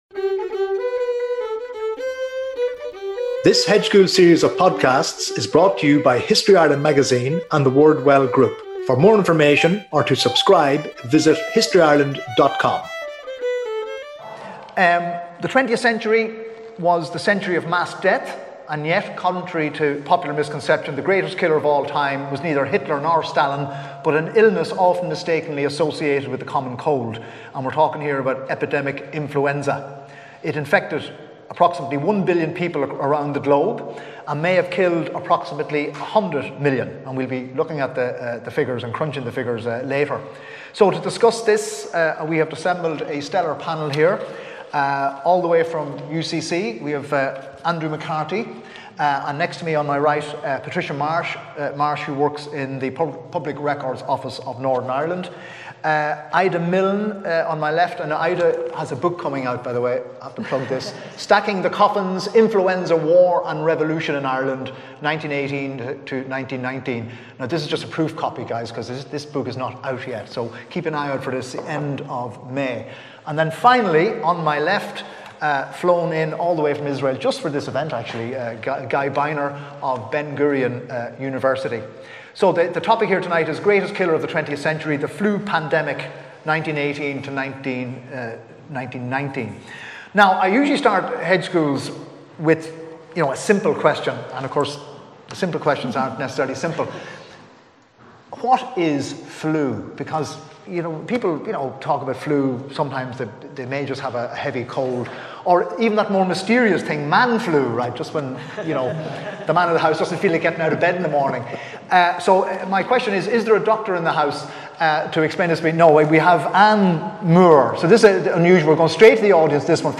Venue: @ CAFE Readers’ and Writers’ Festival, Cobh Library, Co. Cork